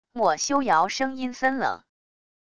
墨修尧声音森冷wav音频